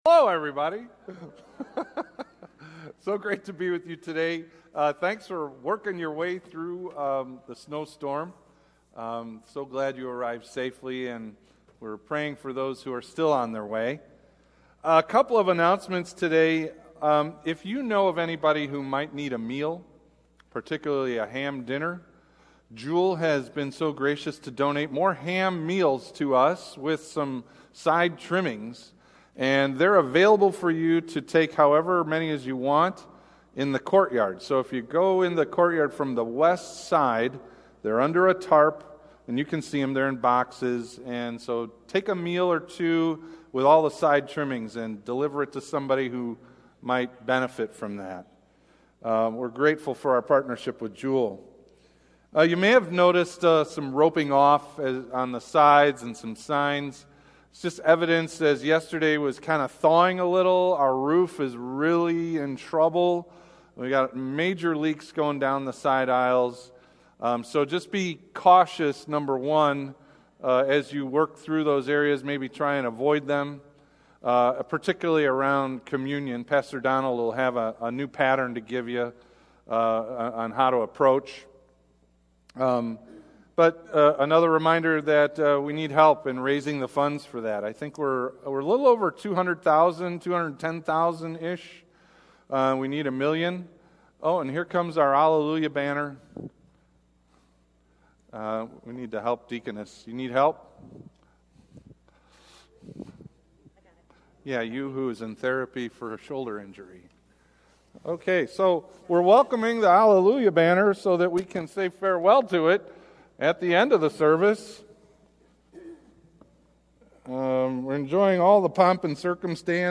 Feb 11 / Worship & Praise – An “E.F. Hutton” Moment – Lutheran Worship audio